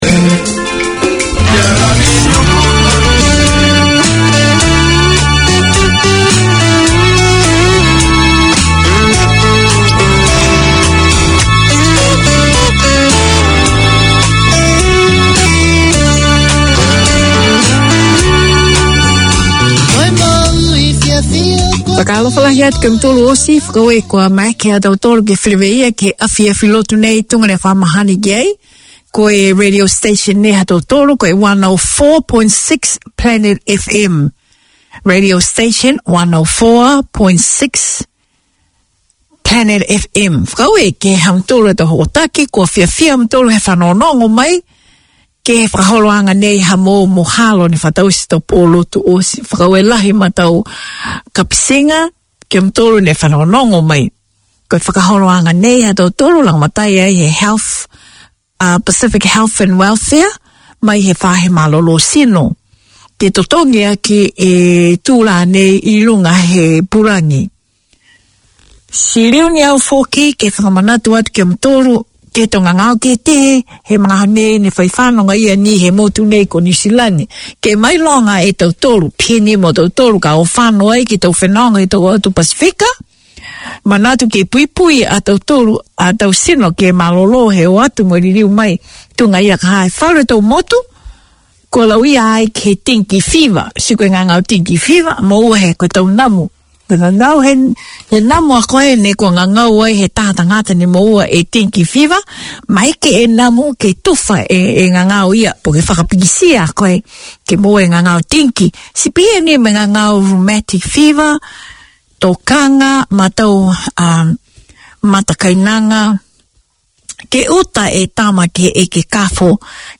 This is co-operative airtime shared between three Niuean Christian churches from around the Auckland region. The churches come to your place with a weekly rotation of services including preaching, singing, playing and praying. There are gospel songs and gospel lessons, praise and testifying.